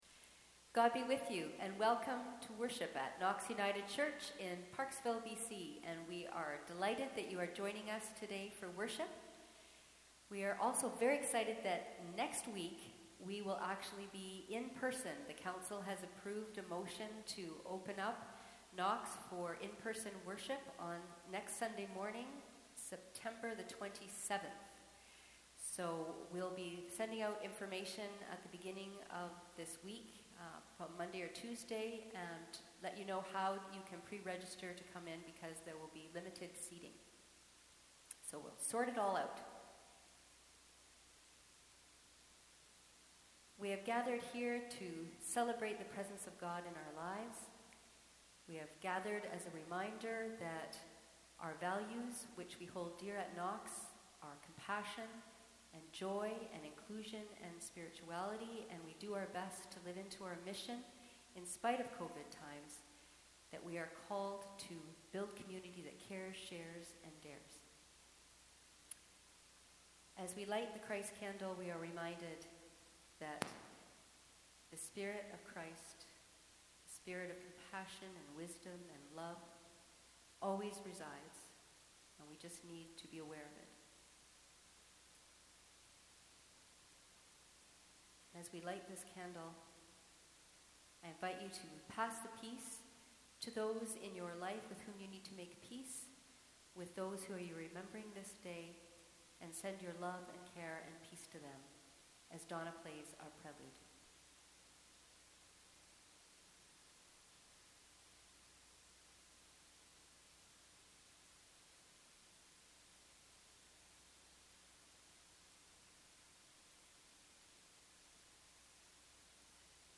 Sermons | Knox United Church